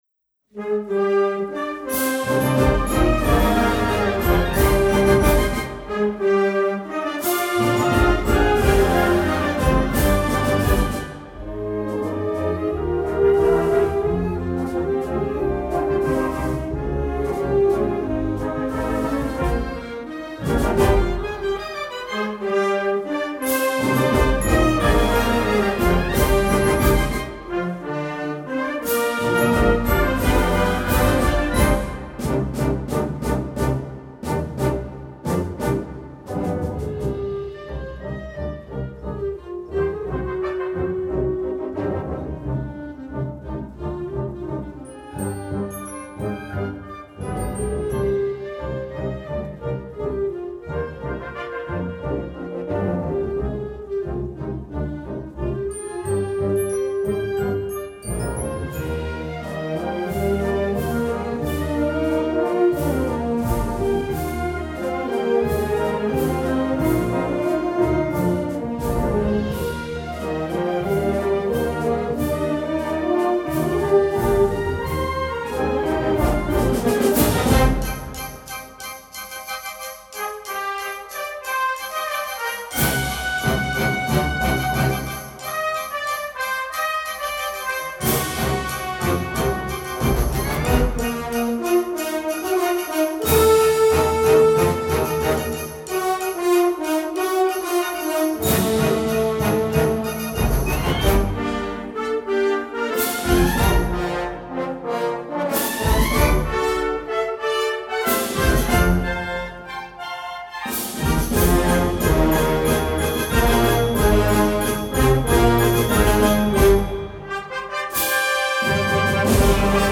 Pasodoble
Gender: Two-step